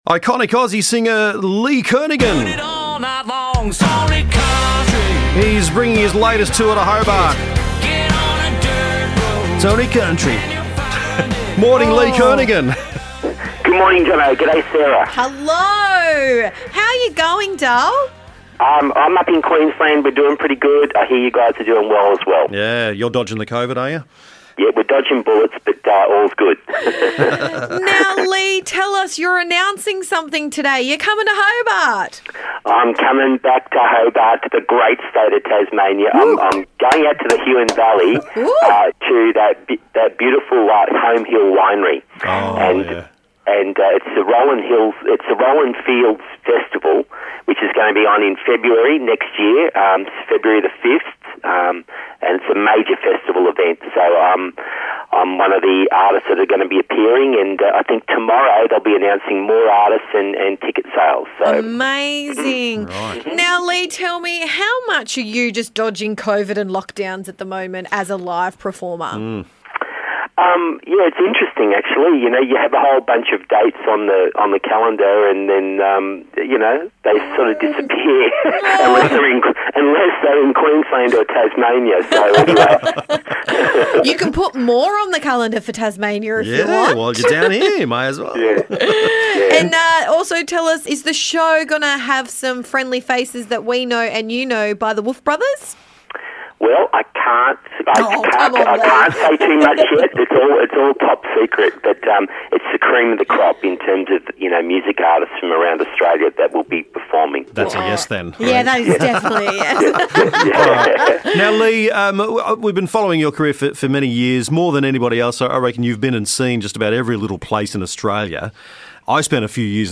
We got to chat to Lee Kernaghan one of Australia’s most loved country music identities and an outback icon. Announcing his Rollin' Fields show in Feb 2022 with special guests to be announced.